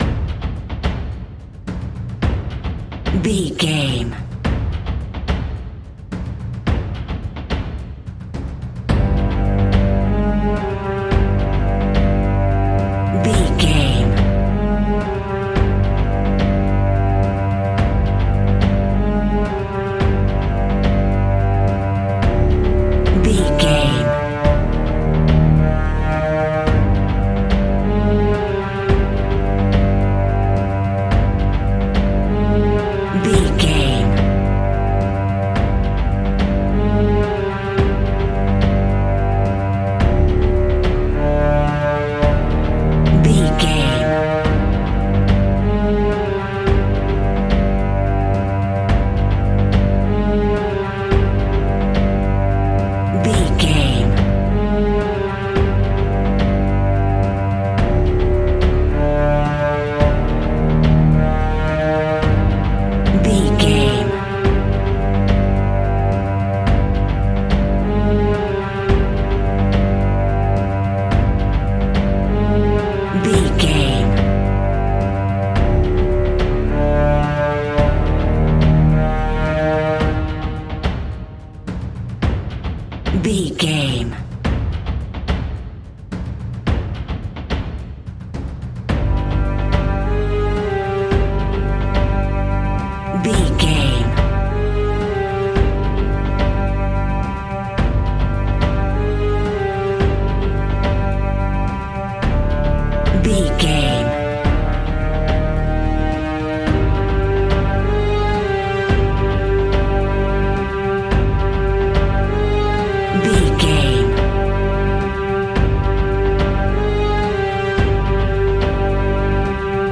Ionian/Major
strings
synthesiser
brass
violin
cello
double bass